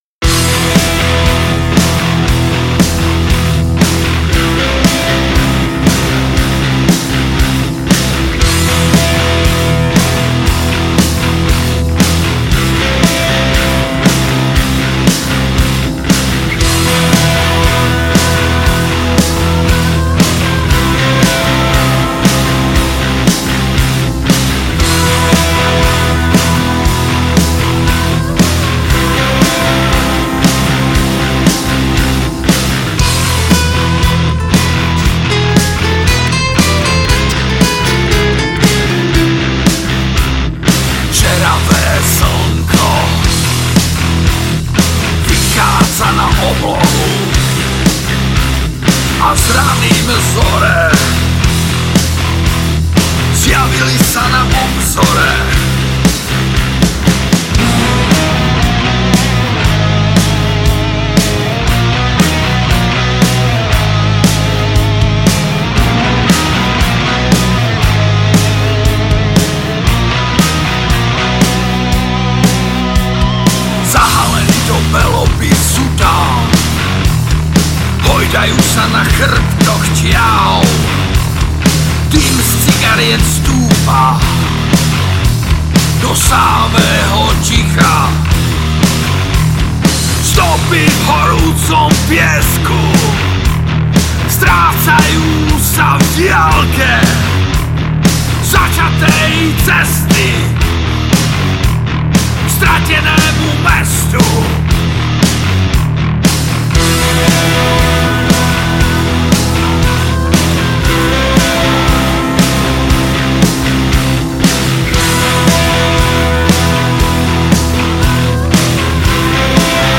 Žánr: Metal/HC